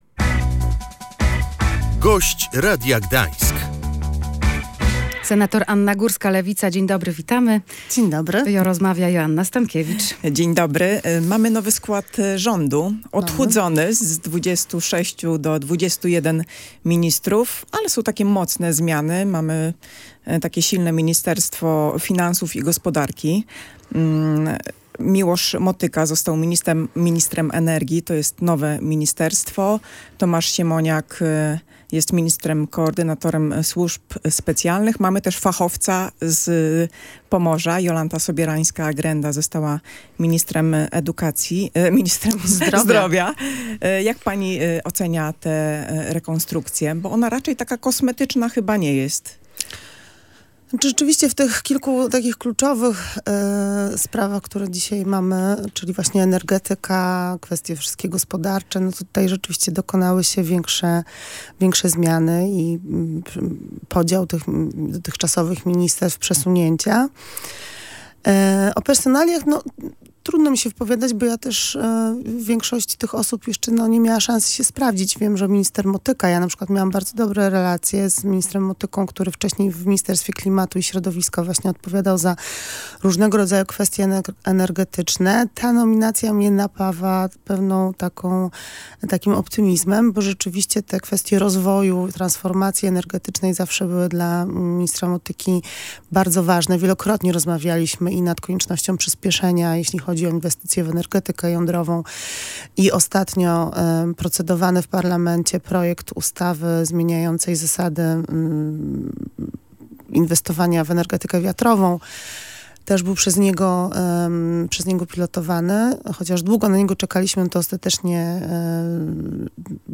Zmiany w rządzie są istotne i napawają optymizmem – oceniła Anna Górska. Senatorka Lewicy, czwartkowy Gość Radia Gdańsk, nadzieje wiąże przede wszystkim z Miłoszem Motyką, który stanie na czele Ministerstwa Energii.